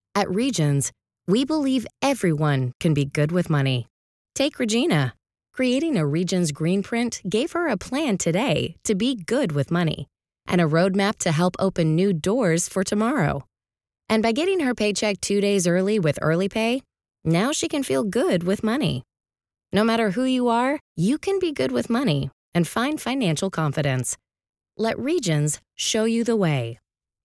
Female
Yng Adult (18-29), Adult (30-50)
Television Spots
National Bank Commercial
Words that describe my voice are Genuine, Informative, Persuasive.
All our voice actors have professional broadcast quality recording studios.